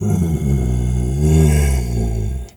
bear_roar_soft_01.wav